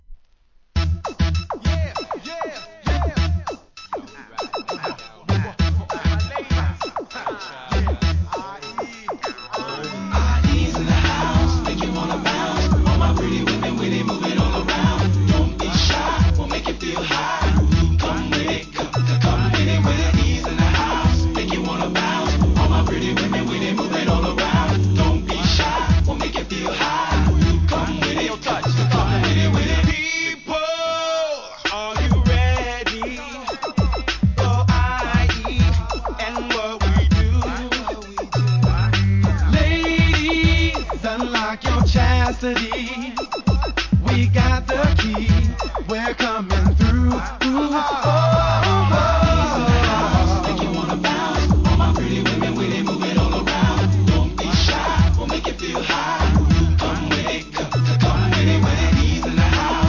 12inch
HIP HOP/R&B